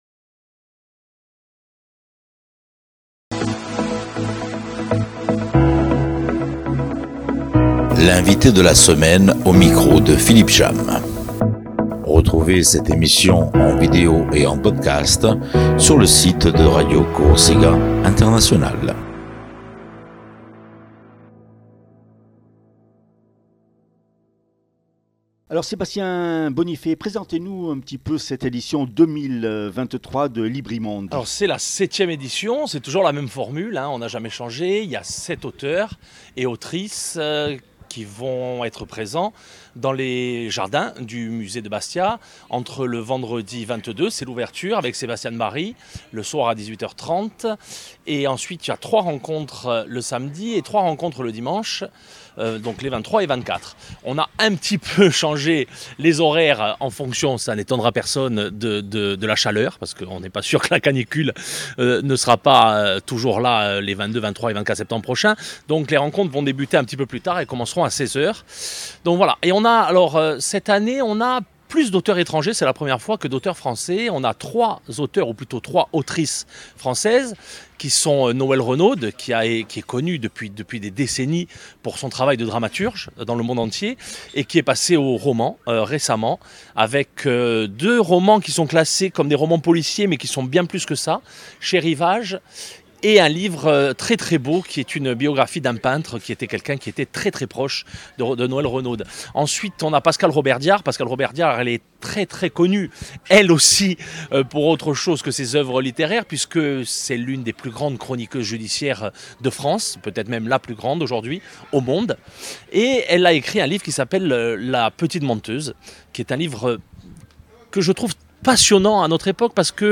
itv